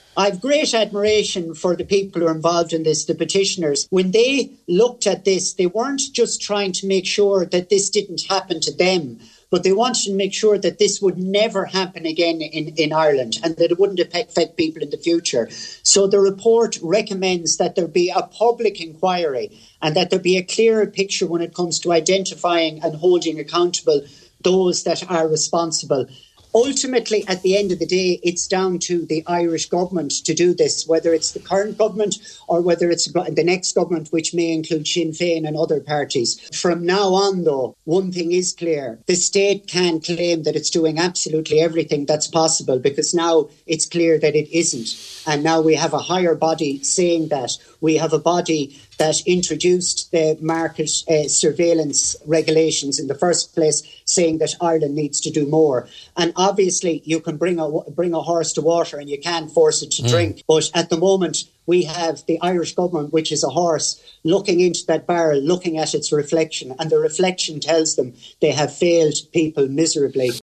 MEP Flanagan says the petitioners have striven to ensure this never happens again, and he believes their vision and foresight is very different to the perspective of the state: